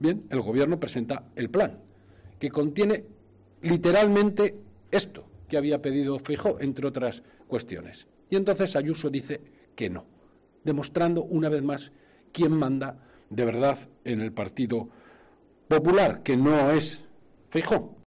López, que ha comparecido en rueda de prensa en Bilbao, ha señalado que "el Gobierno de España, cuando hay problemas, pone encima de la mesa soluciones para intentar atajarlos", mientras que el PP, "cuando hay soluciones, pone problemas para que estas no se puedan llevar a la práctica".